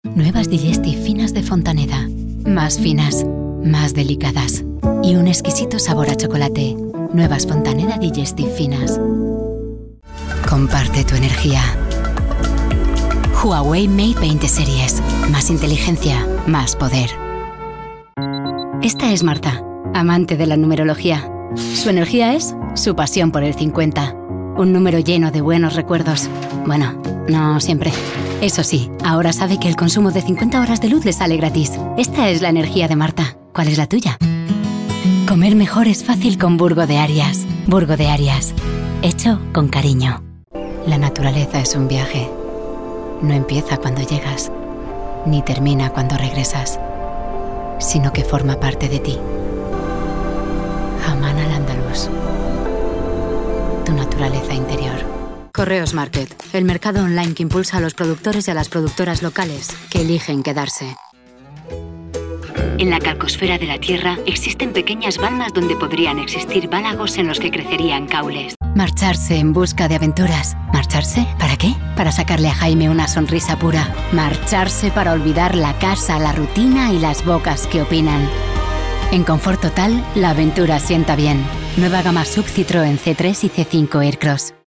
Comercial
Spanish - Neutral
Castilian
Young Adult